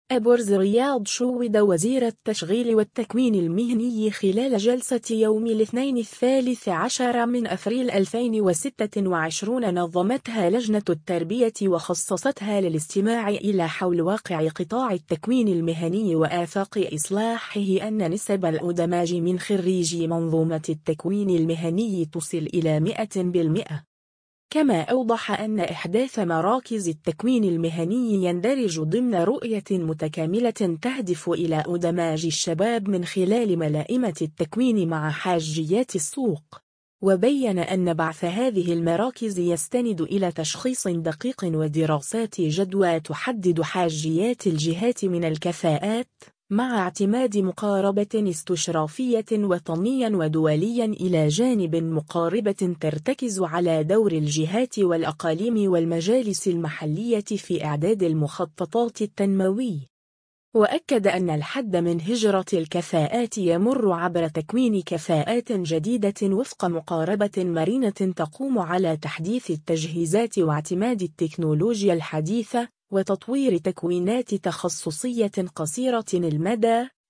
اًبرز رياض شوّد وزير التشغيل والتكوين المهني خلال جلسة يوم الاثنين 13 أفريل 2026 نظمتها لجنة التربية وخصصتها للاستماع إلى حول واقع قطاع التكوين المهني وآفاق إصلاحه أن نسب الإدماج من خريجي منظومة التكوين المهني تصل إلى 100%.